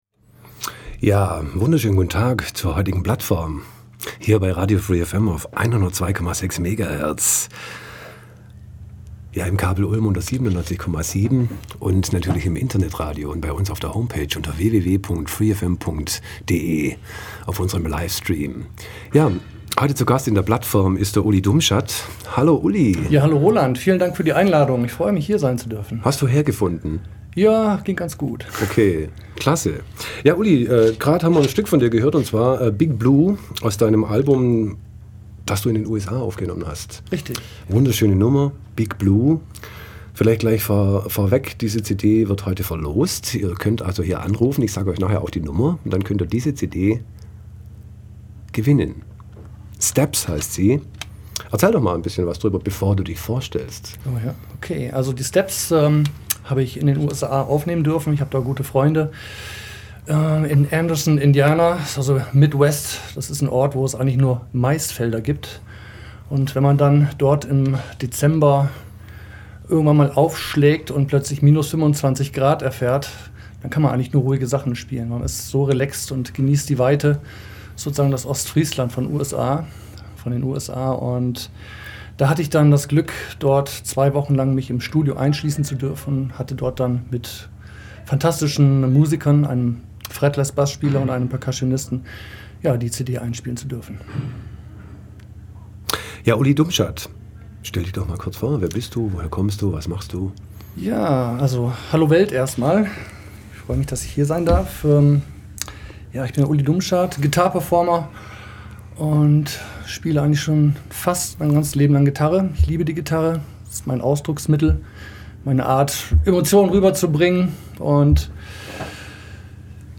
Eine musikalische Klangreise . . .